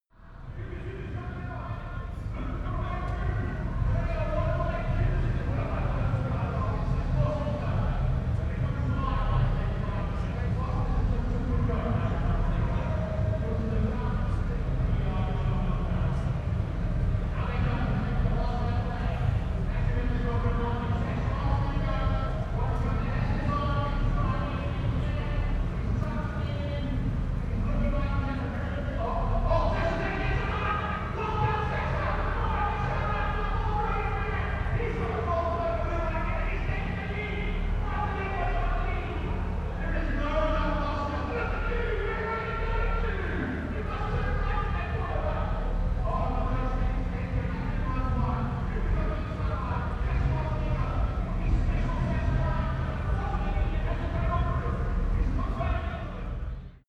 Gemafreie Sounds: Flughafen
Sound 00:43 5687 Airplane English 8